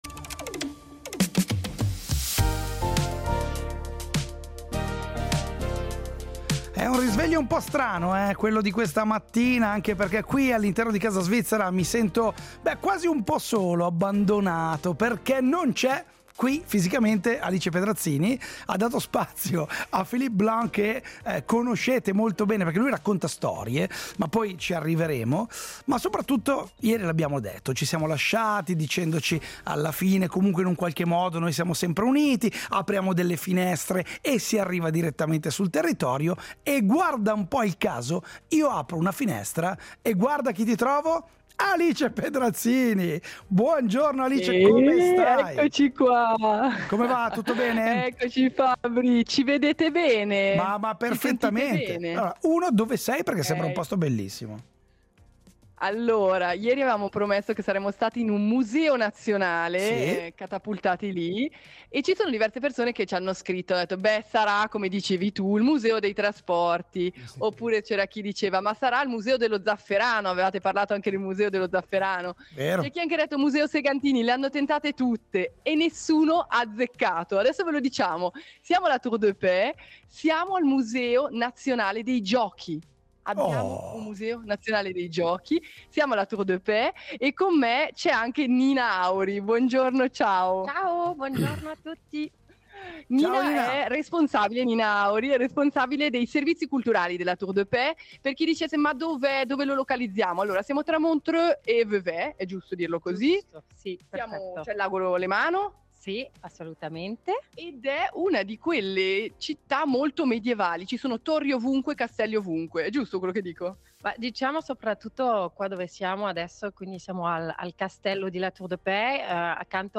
Oggi Casa Svizzera si è svegliata dentro un museo: il Museo Svizzero del Gioco , nel castello di La Tour-de-Peilz.